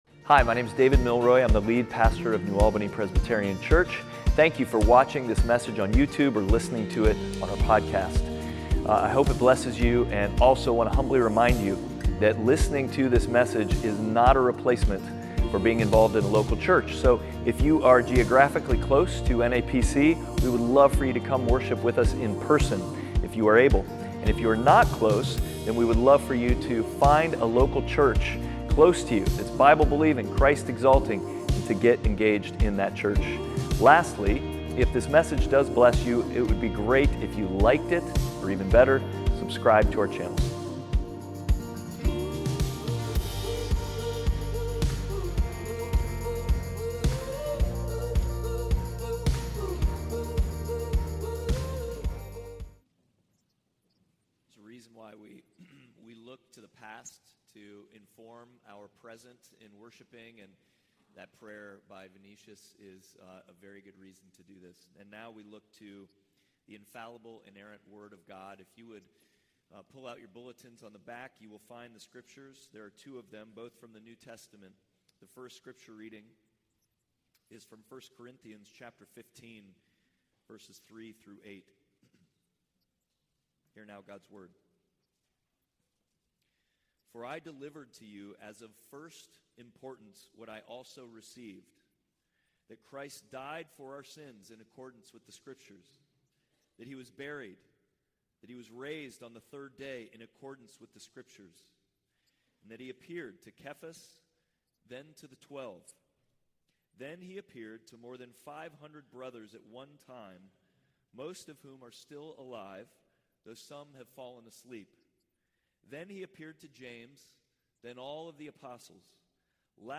Passage: 1 Corinthians 15:3-8, Luke 23:44-46 Service Type: Sunday Worship